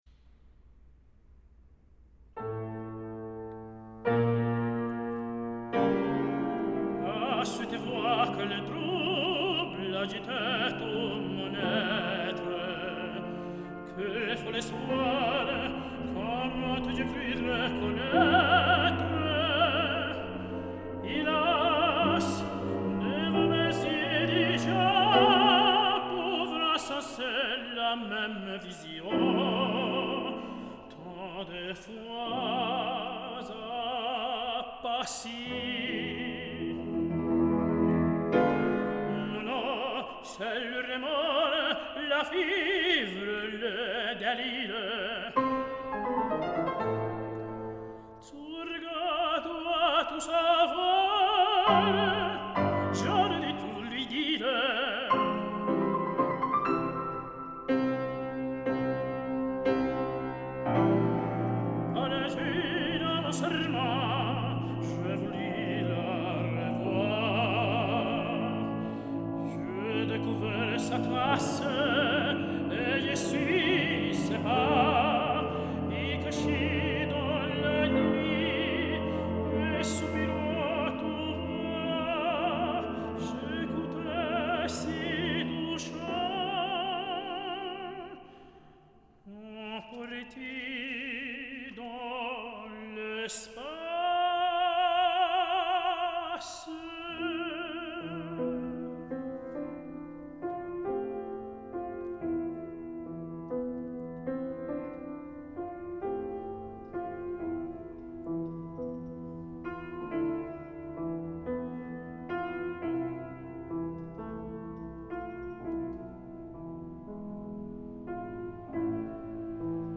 Este Toluca Cantante de Opera se ha caracterizado por su calidez interpretativa, y la belleza de un timbre aterciopelado, ha sido aplaudido, elogiado y aclamado en conciertos, concursos, cursos, festivales y exitosas giras por distintas ciudades del mundo, tales como: Corea del Sur, Alemania, Italia, Francia, España, Portugal, Bélgica, Luxemburgo, Guatemala y la mayor parte del interior de la República Mexicana.
Toluca-Cantante-de-Opera-1-Je-crois.mp3